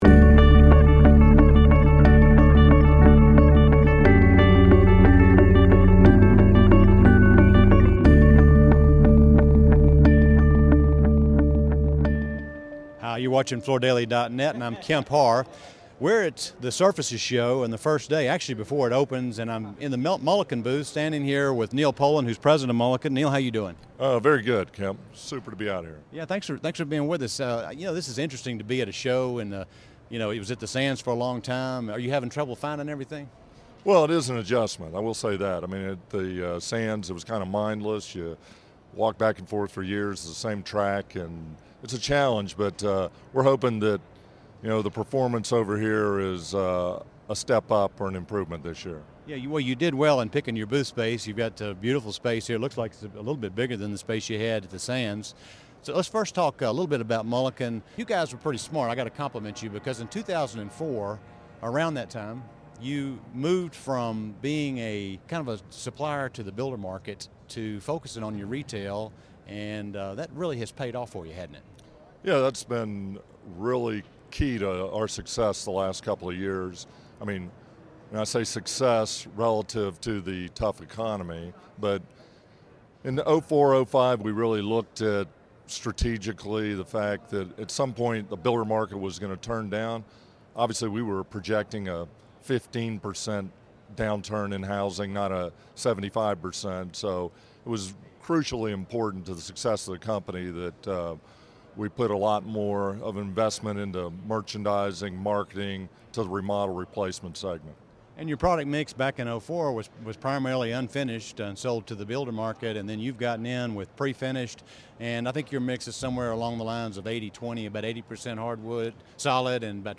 2011—Recorded live from Surfaces 2011